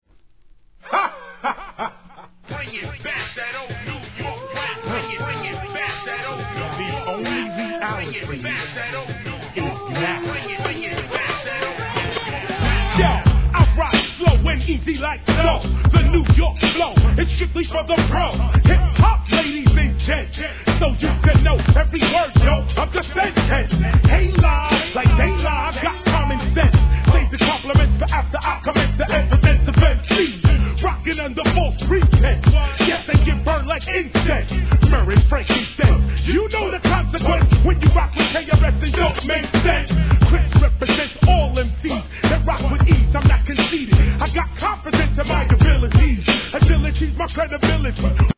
1. HIP HOP/R&B